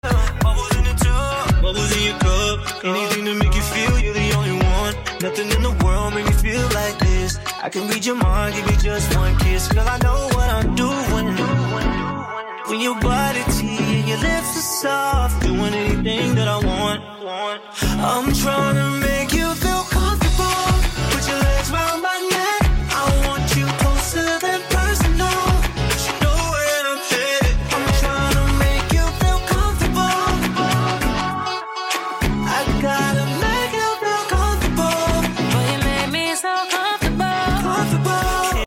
This mashup is a vibe tho.